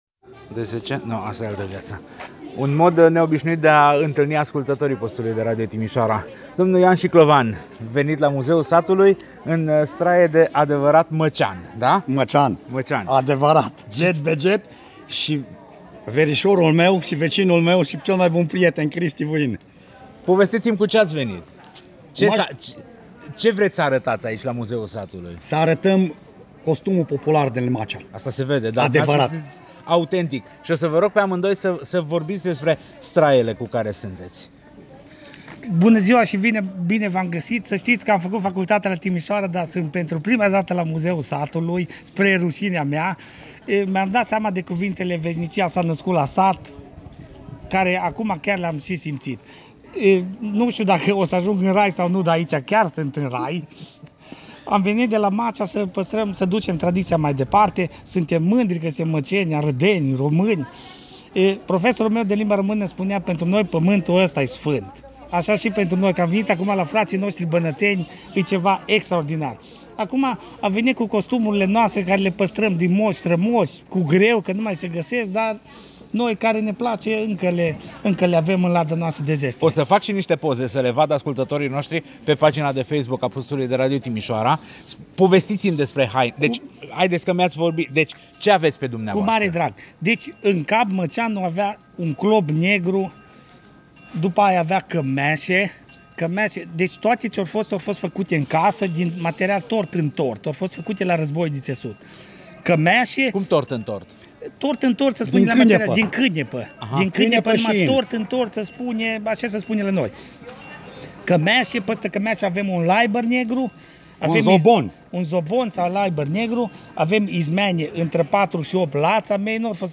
Prezentare făcută de